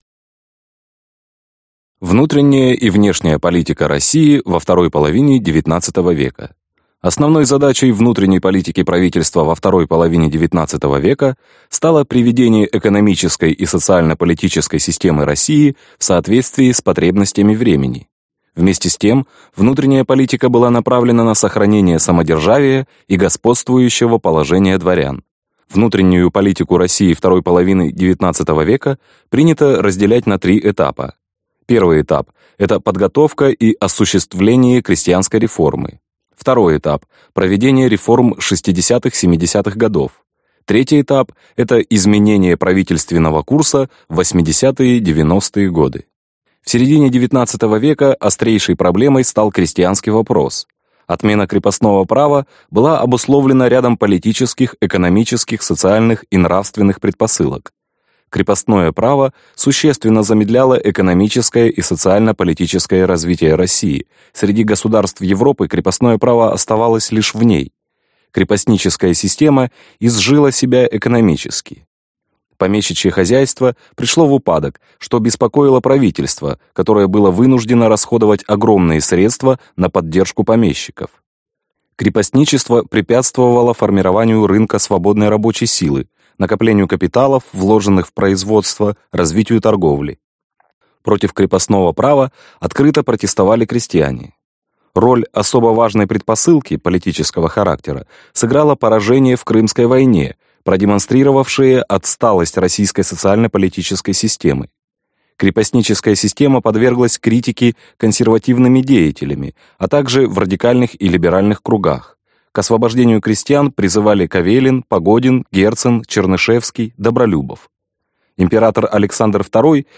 Аудиокнига 11 класс.